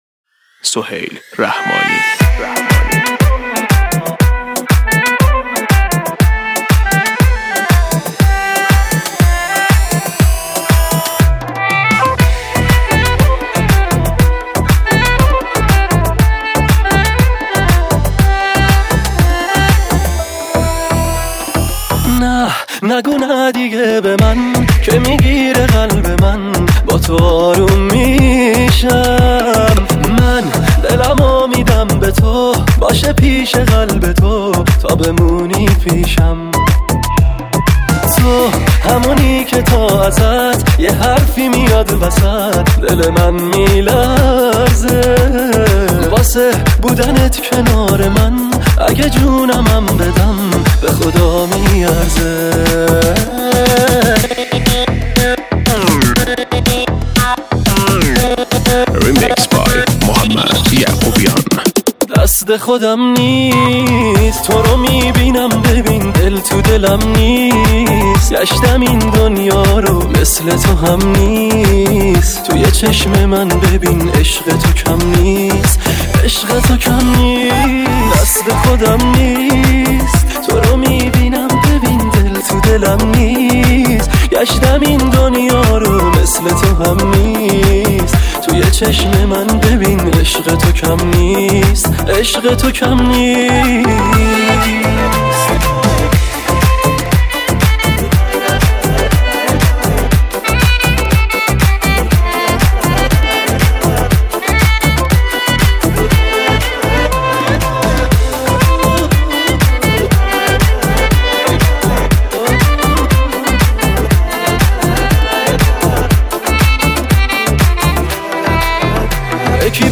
دانلود آهنگهای ریمیکس شاد برای رقص
ریمیکس شاد ارکستی